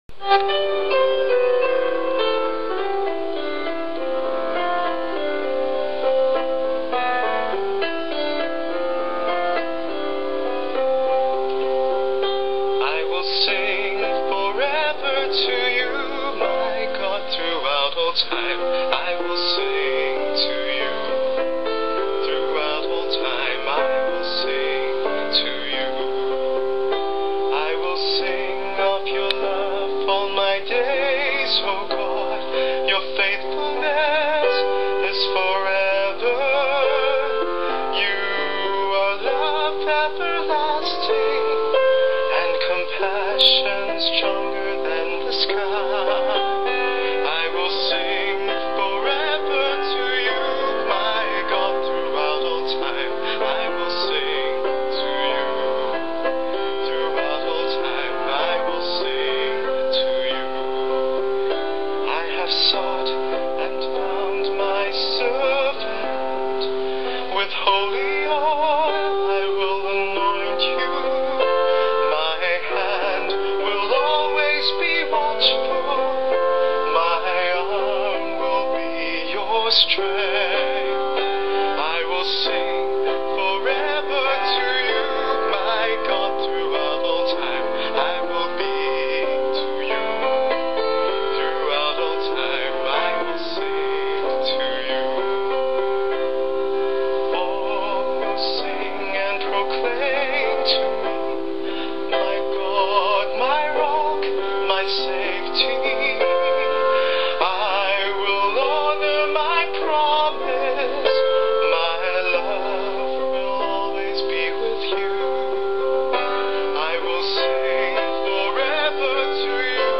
4th Sunday Of Advent, 17-18 December
Psalm Gospel Acc